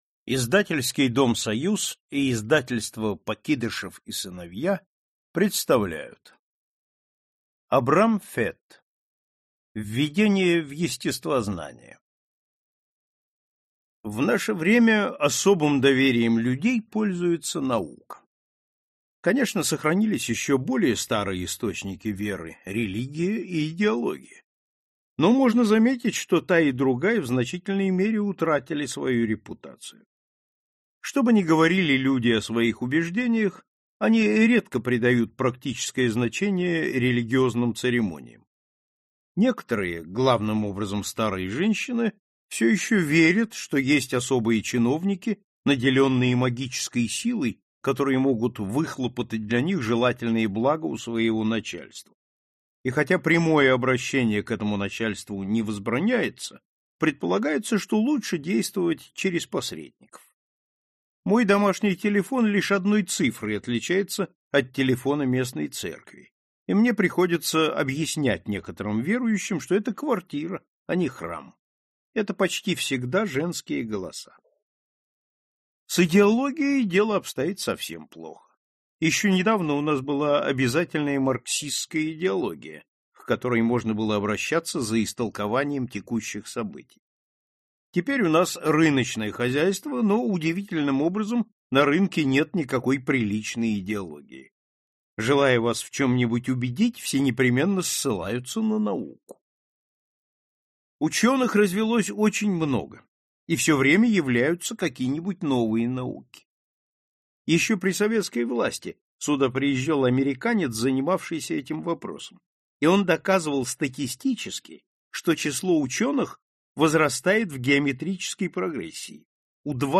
Аудиокнига Введение в естествознание | Библиотека аудиокниг